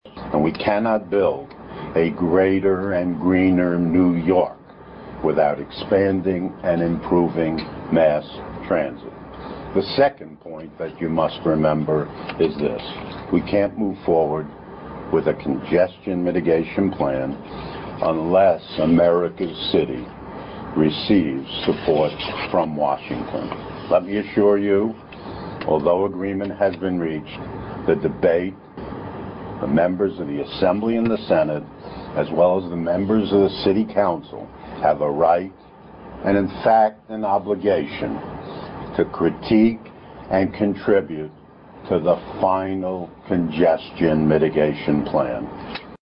The Remarks of Speaker Sheldon Silver
250 Broadway, Manhattan